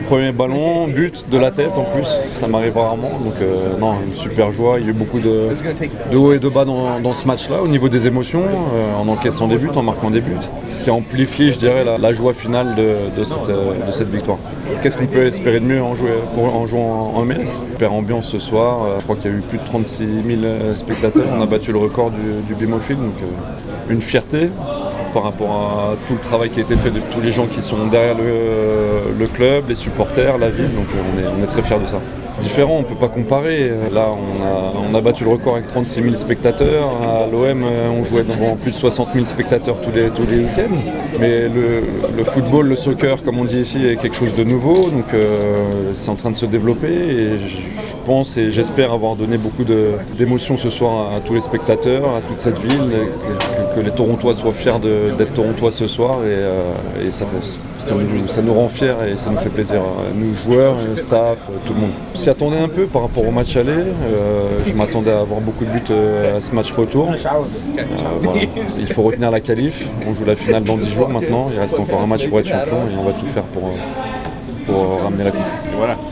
Le interviste del postpartita –